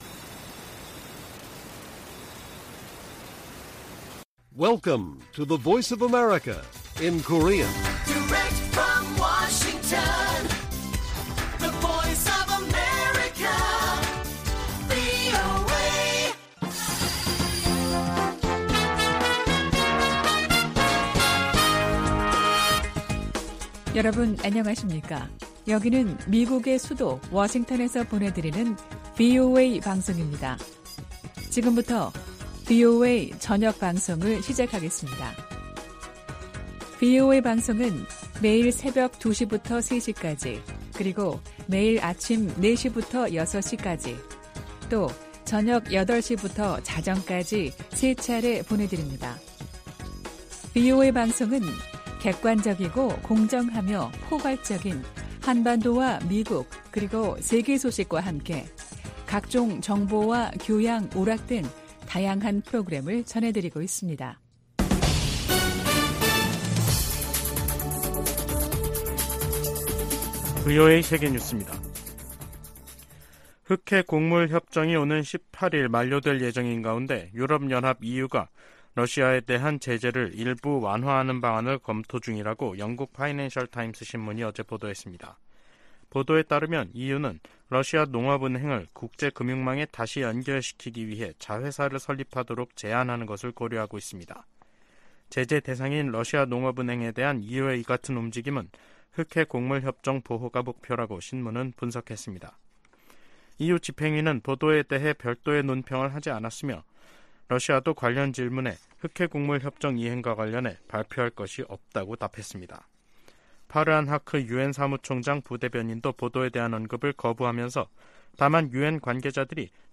VOA 한국어 간판 뉴스 프로그램 '뉴스 투데이', 2023년 7월 4일 1부 방송입니다. 북한이 지난 2016년 2월 7일 발사한 '광명성 4호' 위성이 지구 대기권 재진입 후 소멸된 것으로 확인됐습니다. 미 핵추진 잠수함 미시간함이 한국에 이어 일본에 기항했습니다. 북한이 신종 코로나바이러스 감염증에 따른 마스크 의무를 해제한 것으로 알려지면서 국경 개방도 가능하다는 관측이 나오고 있습니다.